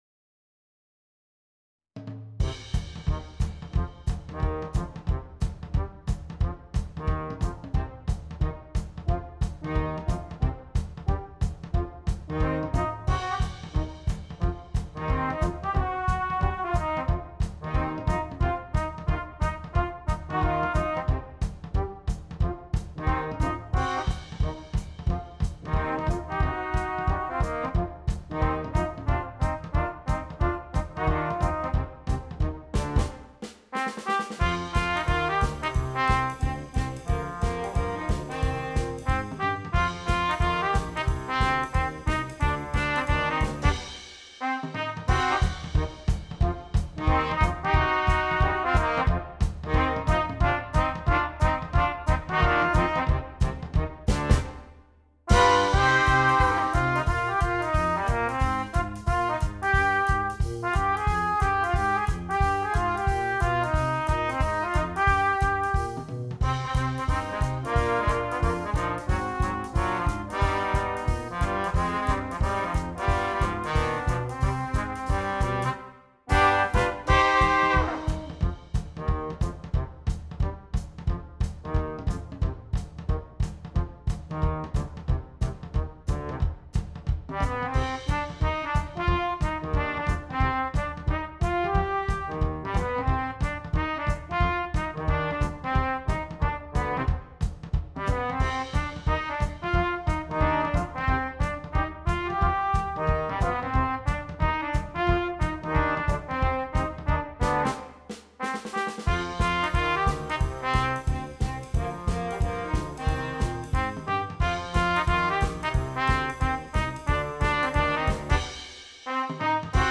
Brass Quintet (optional Drum Set)